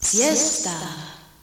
Here are some jingles.